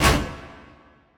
Kick SwaggedOut 2.wav